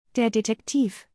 With audio by a native speaker, you can practice your pronunciation and secure these essential words in your memory!